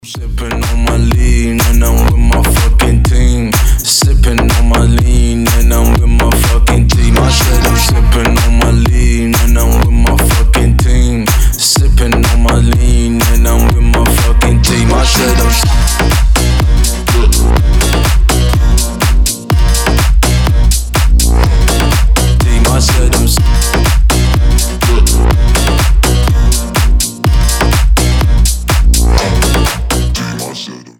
• Качество: 320, Stereo
мужской голос
громкие
восточные мотивы
EDM
мощные басы
Bass House
качающие
Крутая басовая музыка на звонок